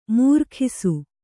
♪ mūrkhisu